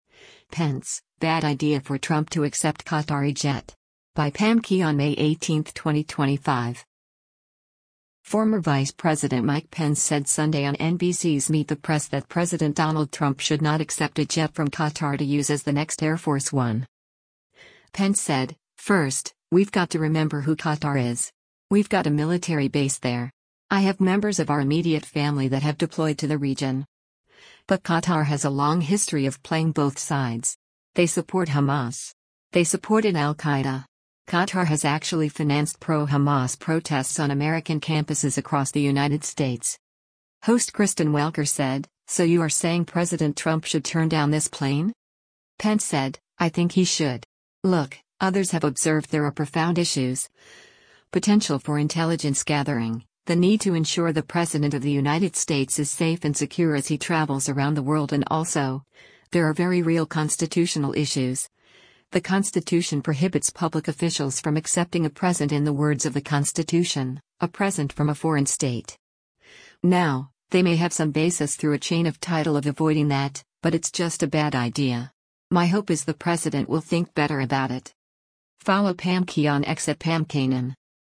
Former Vice President Mike Pence said Sunday on NBC’s “Meet the Press” that President Donald Trump should not accept a jet from Qatar to use as the next Air Force One.